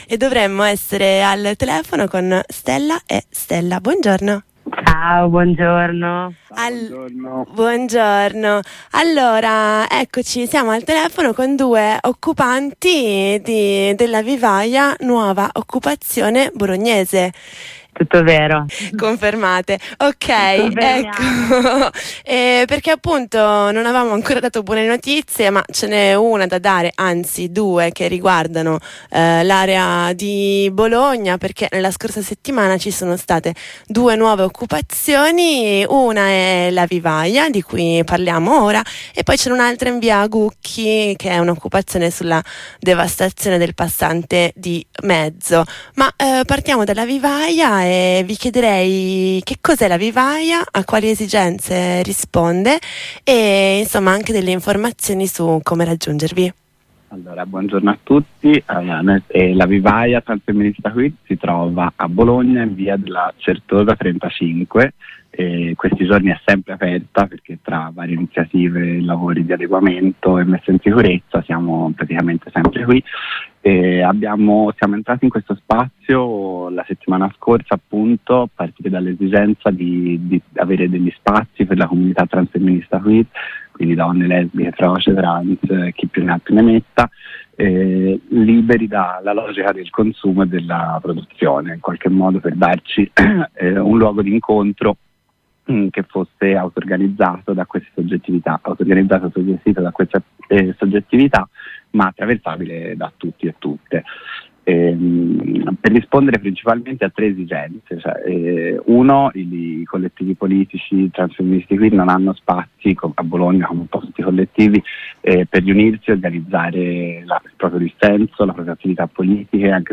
Abbiamo parlato con due occupanti di quest’ultima, che da una settimana si prendono cura e condividono gli spazi del giardino e dei locali dell’ex vivaio Gabrielli, da molti anni chiuso e in stato di t